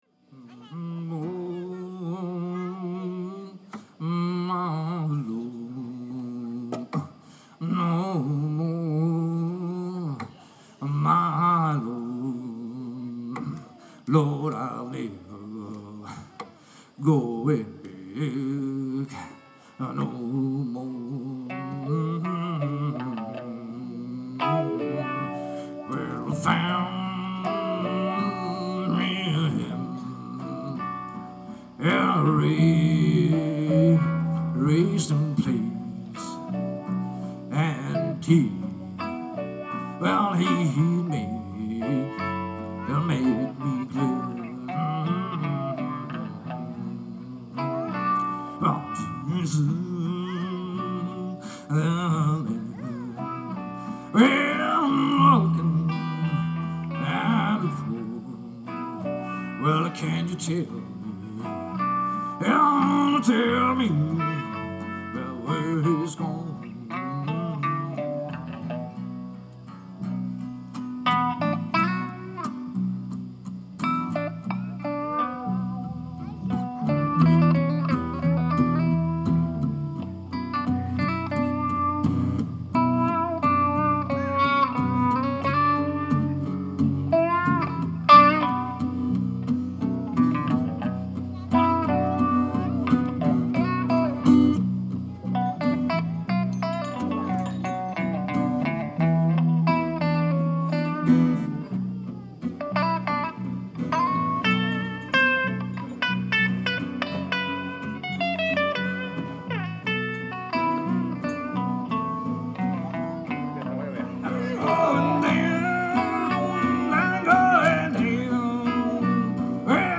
tots ells de Lavern i que des de fa un any queden per gaudir tocant blues.
Aquí teniu la peça que va obrir el concert. So ambient.
La seva proposta és de petit format, acústica però elèctrica i amb suport visual especialment editat per a l’ocasió.